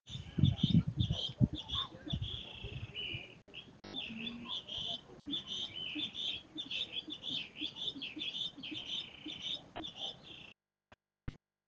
Plumbeous Rail (Pardirallus sanguinolentus)
Life Stage: Adult
Detailed location: Parque Municipal Finky
Condition: Wild
Certainty: Observed, Recorded vocal